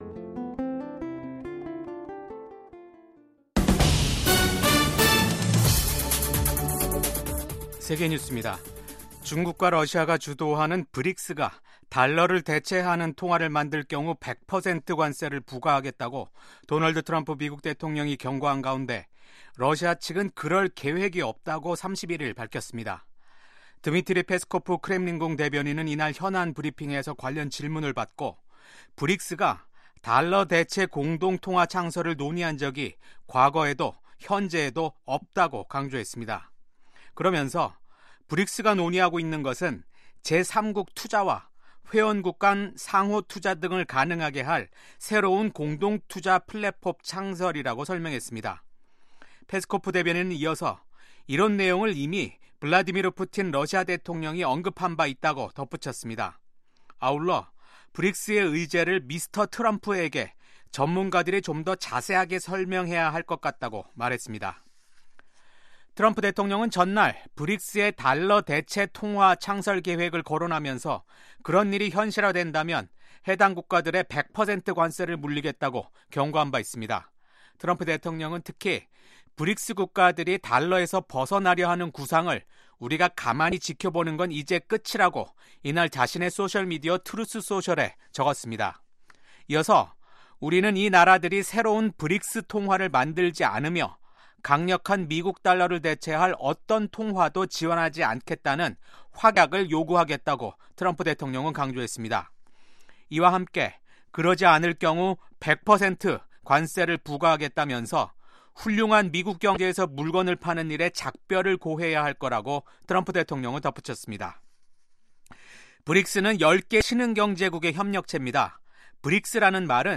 VOA 한국어 아침 뉴스 프로그램 '워싱턴 뉴스 광장'입니다. 트럼프 2기 행정부 국가정보국장으로 지명된 털시 개버드 전 하원의원은 미국이 북한의 핵과 미사일 위협을 낮추는 데 대북 정책의 우선순위를 둬야 한다고 밝혔습니다. 미국 육군장관 지명자는 북한, 중국, 러시아, 이란이 미국에 맞서 협력하고 있다고 지적했습니다.